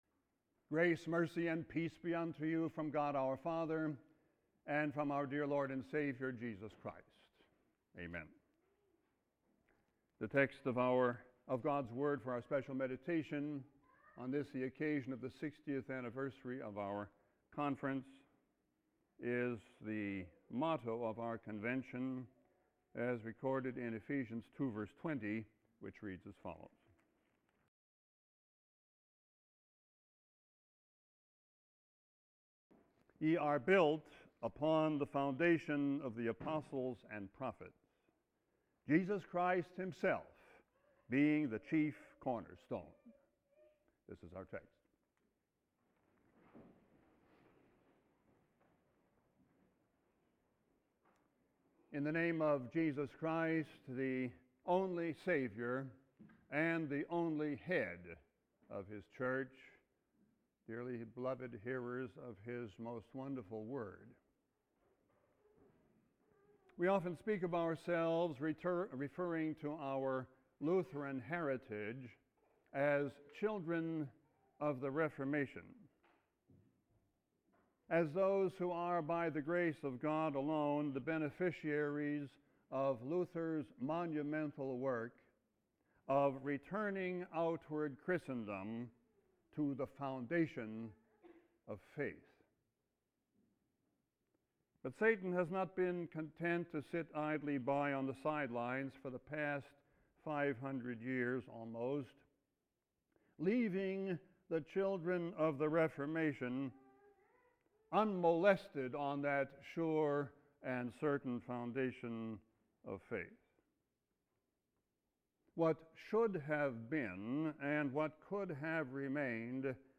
Sermon-6-24-11.mp3